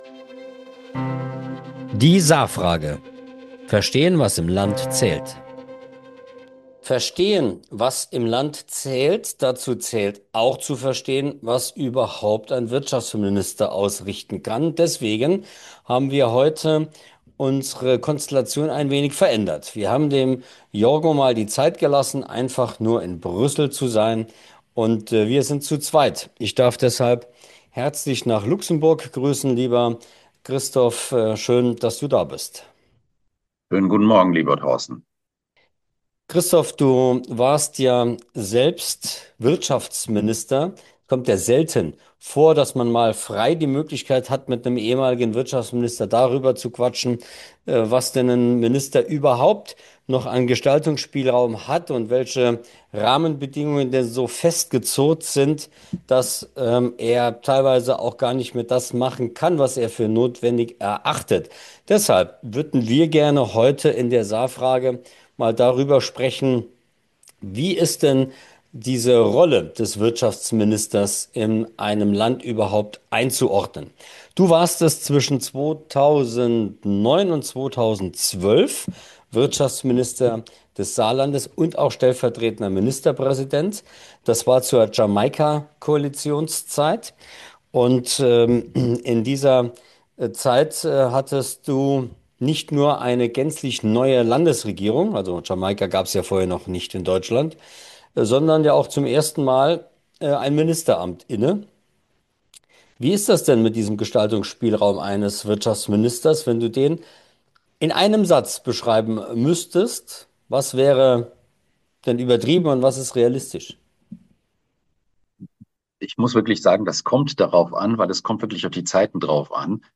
Christoph Hartmann, ehemaliger Wirtschaftsminister des Saarlandes, spricht über den tatsächlichen Gestaltungsspielraum eines Ministers und die verschiedenen Faktoren, die seine Macht begrenzen. Ein zentraler Aspekt, den Christoph Hartmann anspricht: die politischen Rahmenbedingungen.